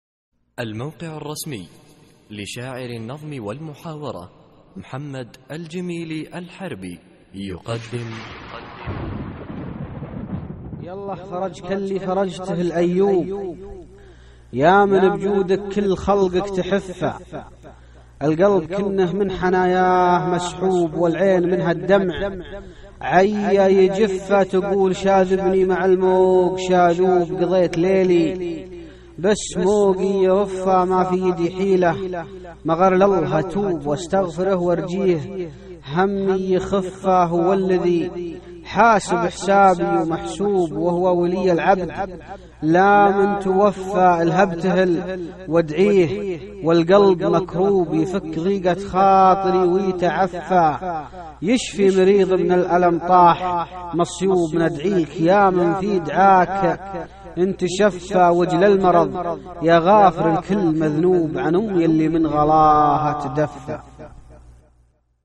القصـائــد الصوتية